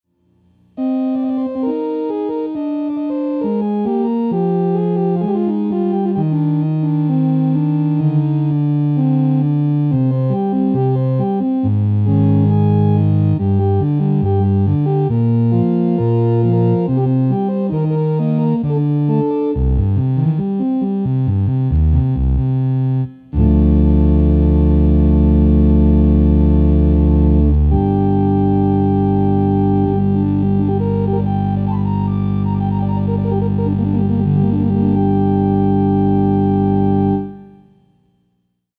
SpecialeffectPianoClavichordsolo.mp3 This file shows the special effect decay mode being used, which, unlike the other two, does not taper off but rather holds out the note like an organ would. It has a rather heavy bottom end when using this feature with the lower keys, as you will hear...Kind of sounds like an electrified harmonium if such a sound can be conjured.
propianoSpecialeffectPianoClavichordsolo.mp3